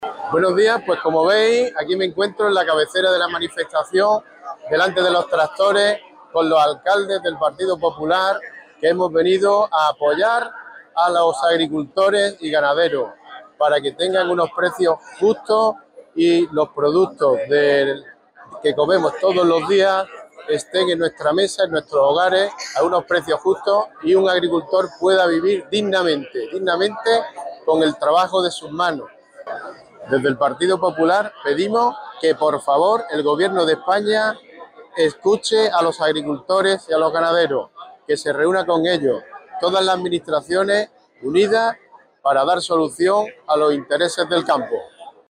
El PP de Málaga se ha posicionado hoy junto a los agricultores y ganaderos movilizados en la capital, donde alcaldes populares de toda la provincia les han mostrado su apoyo, encabezados por el regidor de Antequera, Manuel Barón, y el vicesecretario popular y primer edil de Riogordo, Antonio Alés.